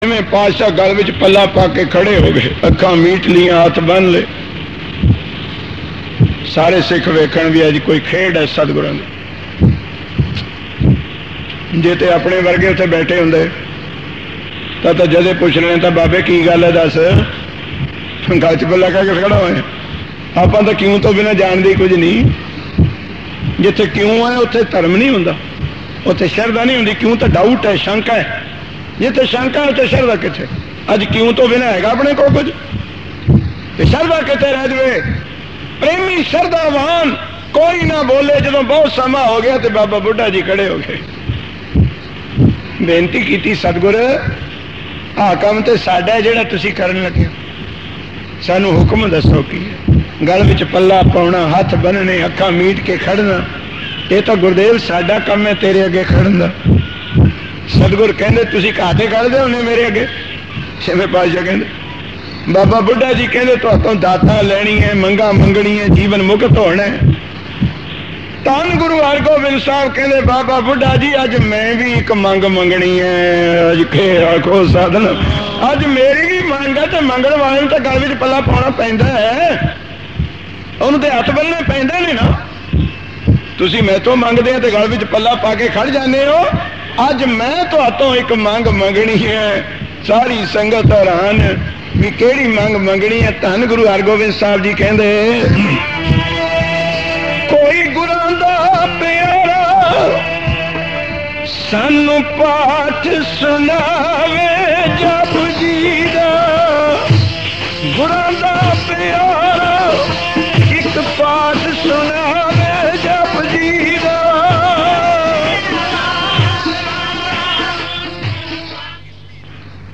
Kirtan with katha
Genre: Gurmat Vichar